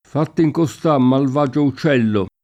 costà [koSt#+] avv.